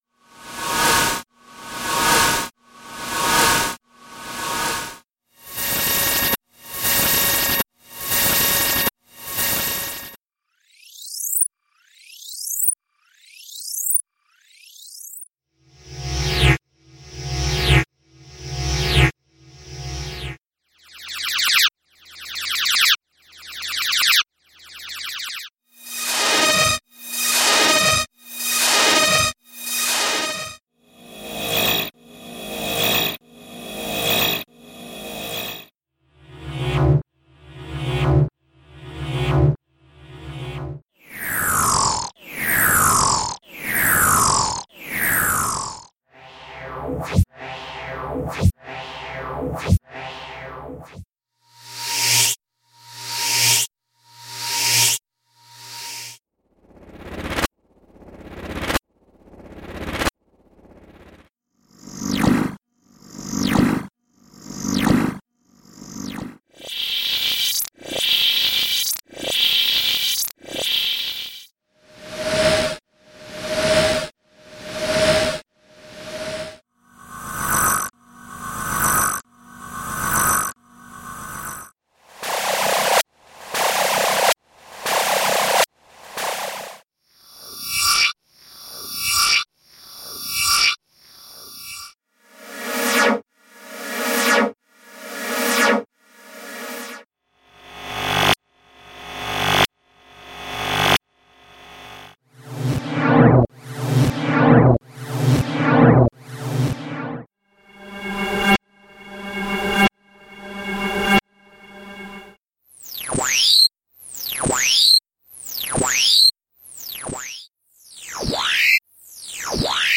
Sound Effects - SIGNALS - V5 - p1
Signals Actual Length: 1 Minute (60 Sec) Each Sound.